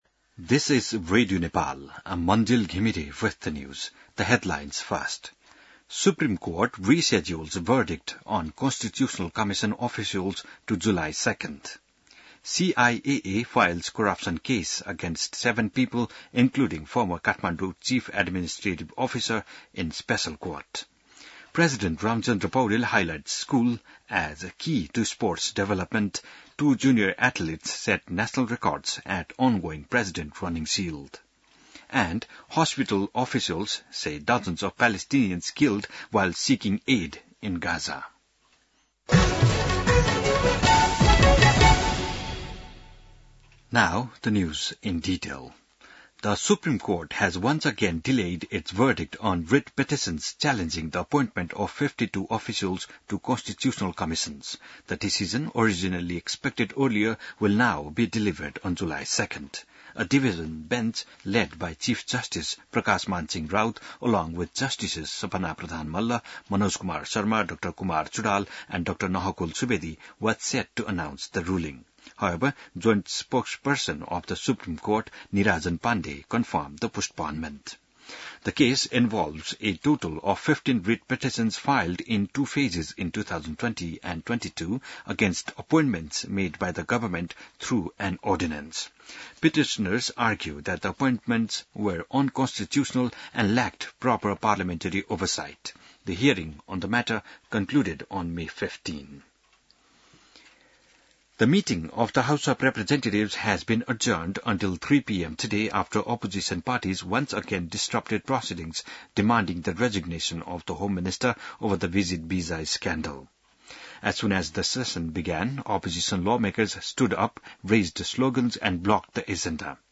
An online outlet of Nepal's national radio broadcaster
बिहान ८ बजेको अङ्ग्रेजी समाचार : २९ जेठ , २०८२